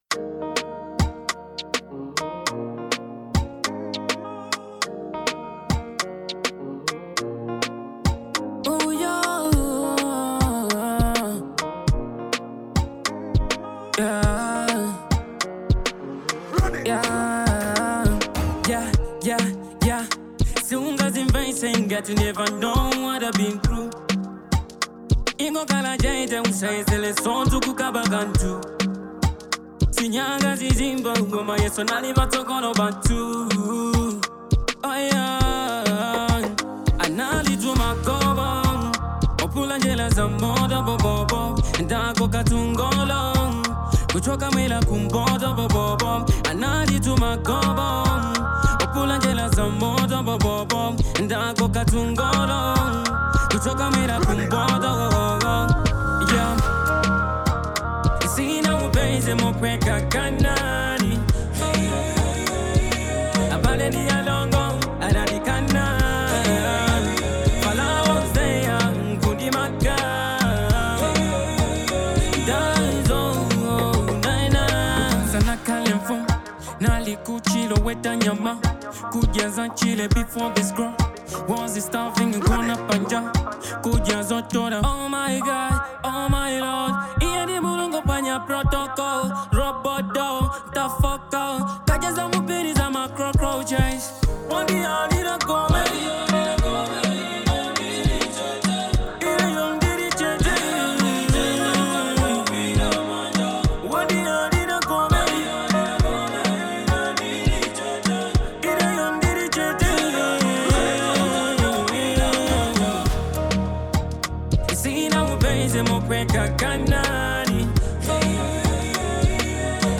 Genre : Afro Beat